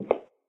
inside-step-2.ogg.mp3